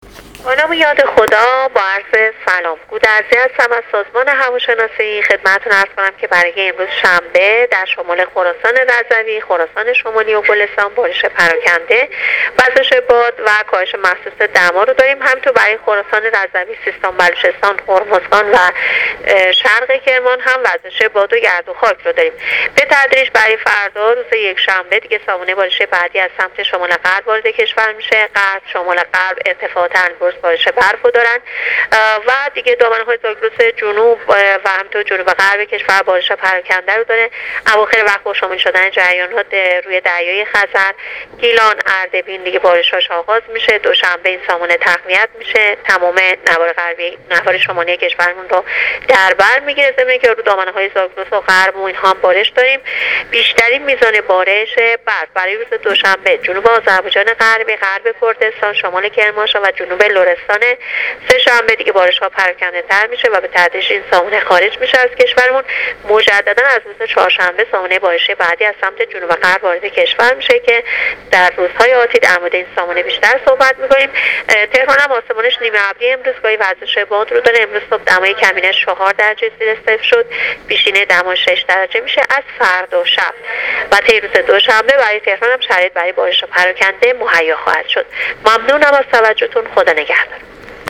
گزارش رادیو اینترنتی از آخرین وضعیت آب‌و‌هوای ۲۶ بهمن‌ماه ۱۳۹۸